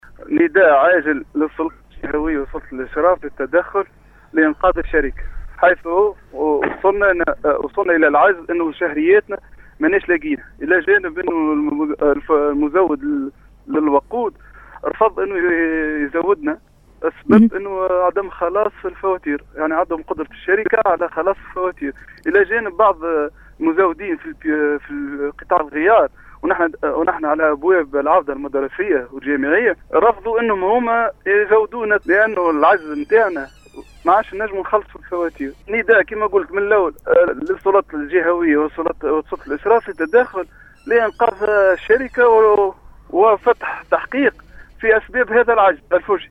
في اتصال باذاعة السيليوم أ ف أم